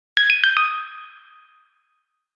sms 4